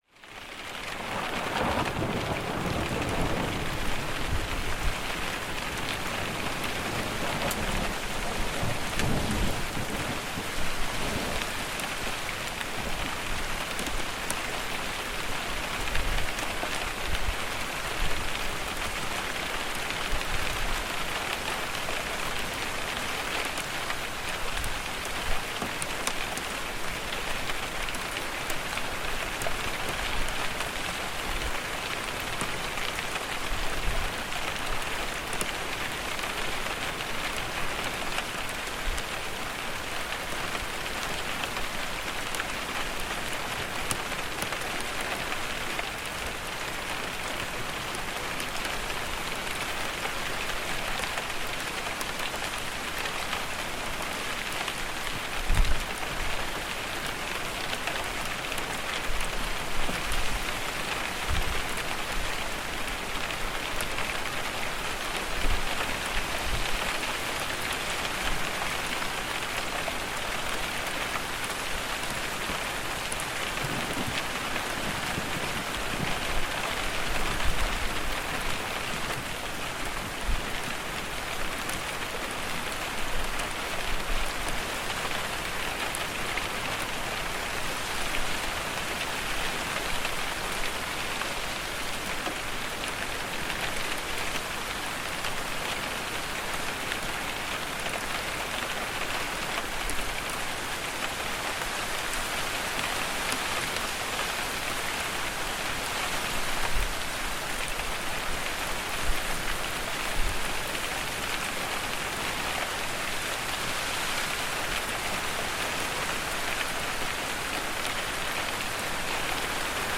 Window Rain & Thunder—Heavy Storm for Deep Calm